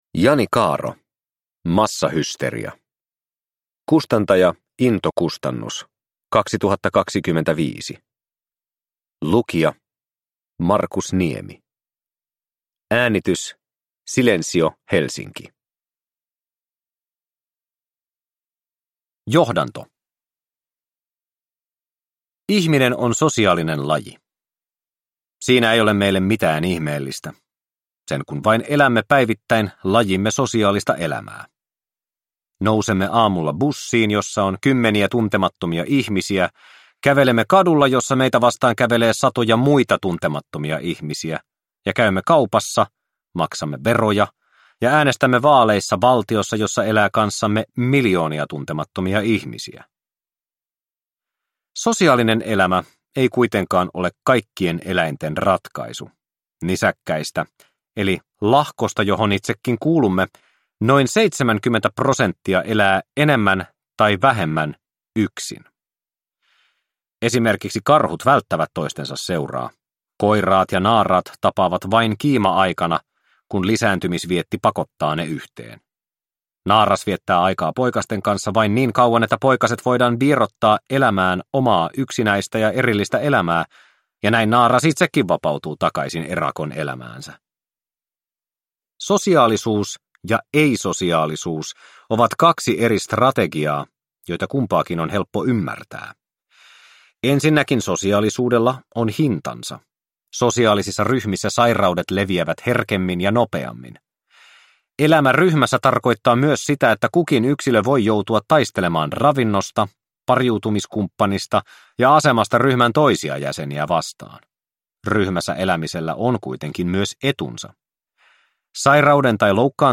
Massahysteria – Ljudbok